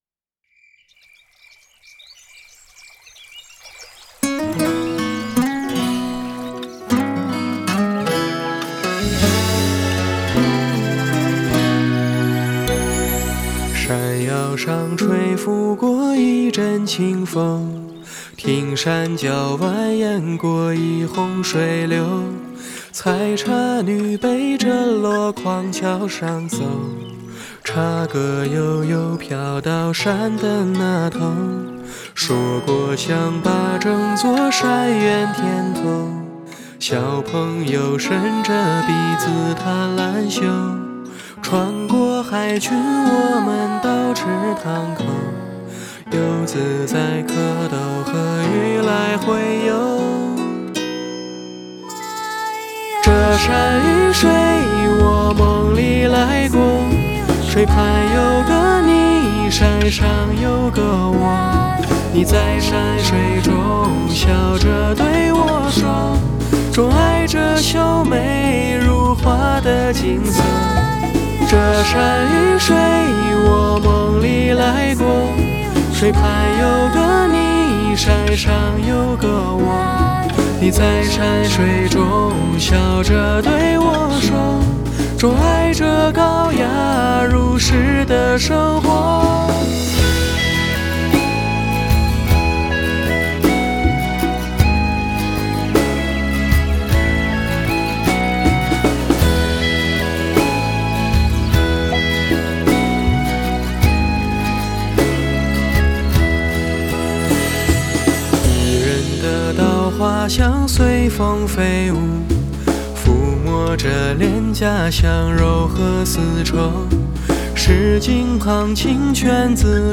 This time, I'd like to share with you a folk song that describes the comfort, ease and relaxation of rural life.